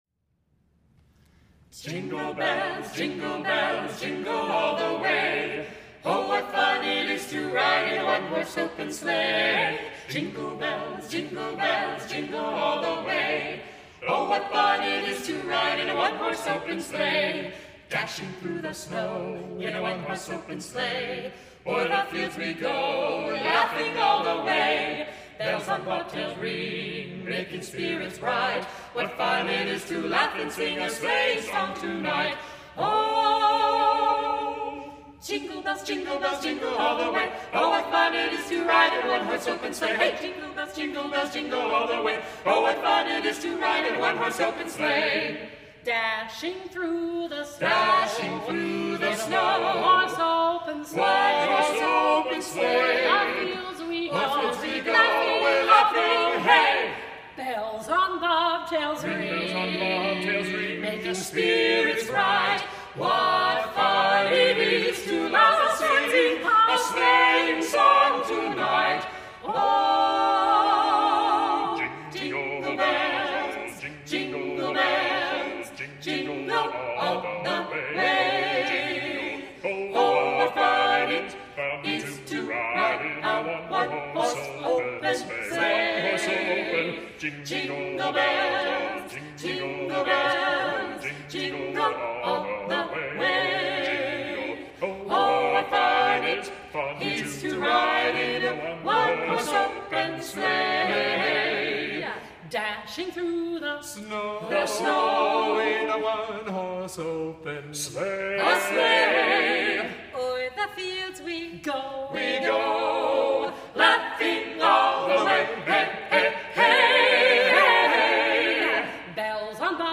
This holiday season why not spoil your party guests, employees or customers with a quartet of professional singers performing Holiday classics?
carolers_jingle_bells.mp3